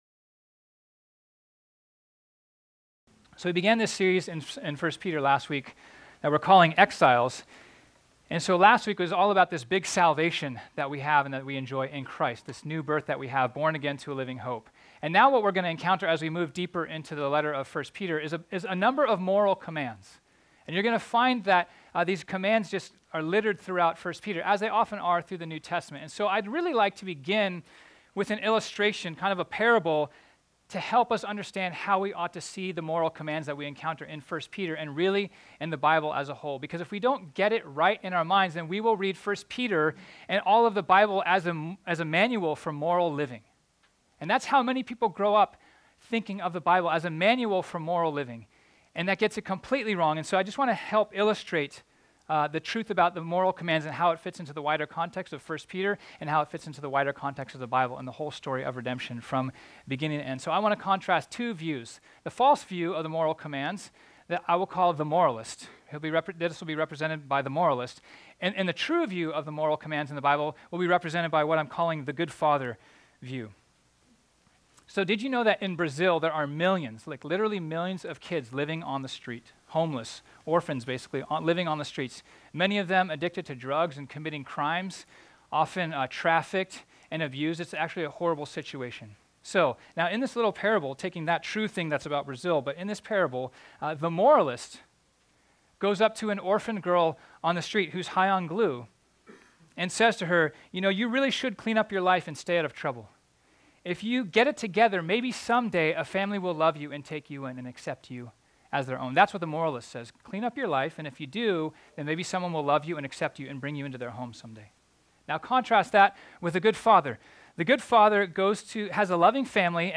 This sermon was originally preached on Sunday, February 18, 2018.